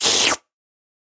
Звуки лизания
звук слизывания человеком